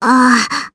Gremory-Vox_Damage_jp_05.wav